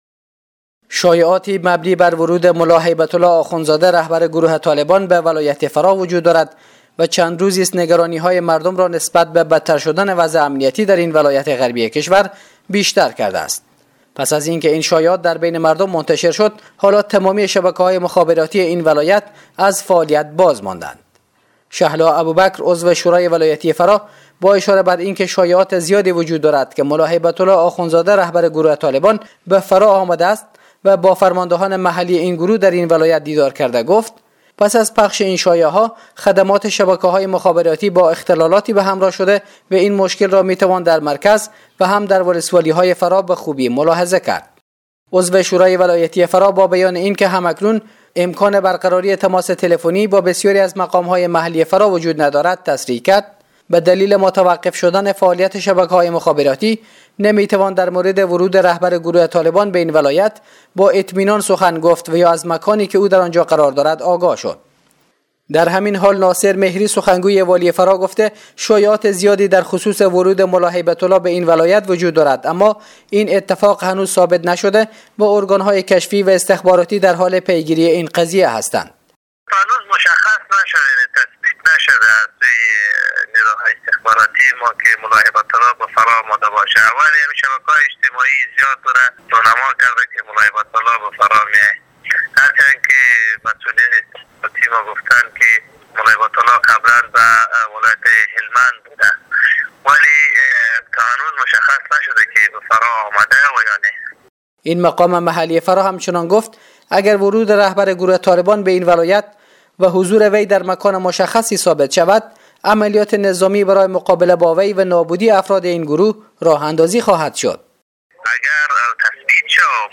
گزارش : سفر رهبر طالبان به فراه، شایعه یا واقعیت!؟